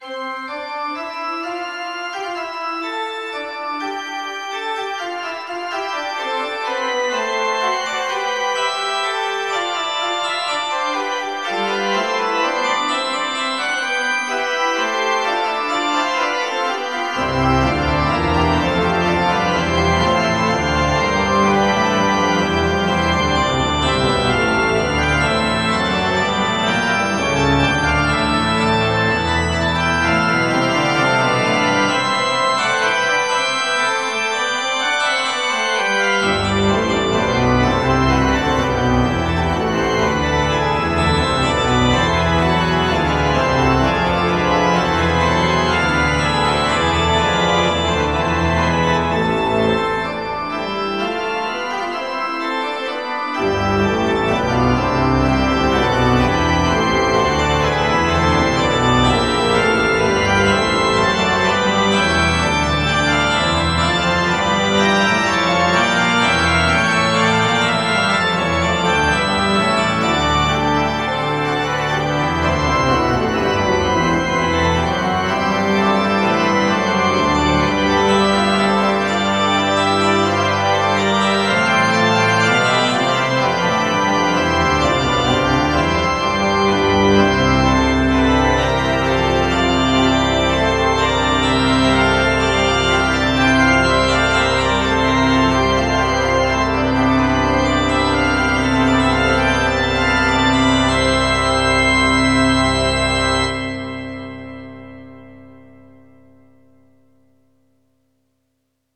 CATHEDRAL -R.wav